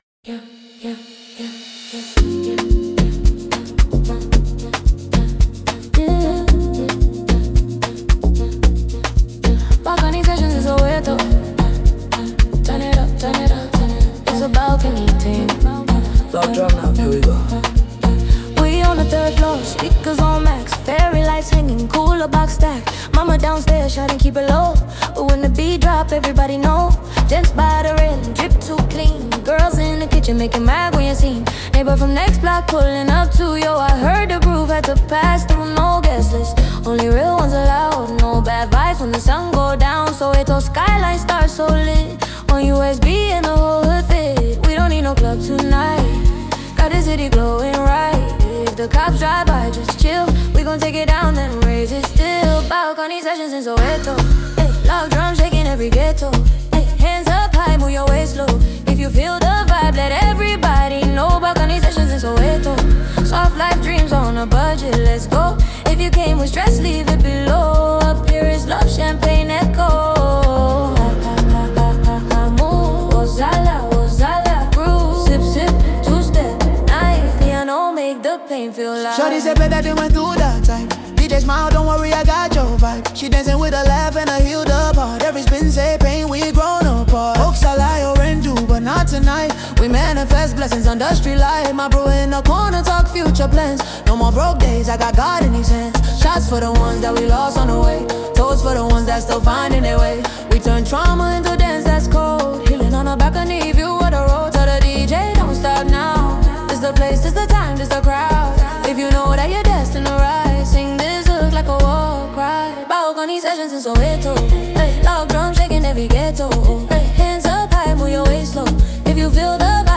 Amapiano 2025 Non-Explicit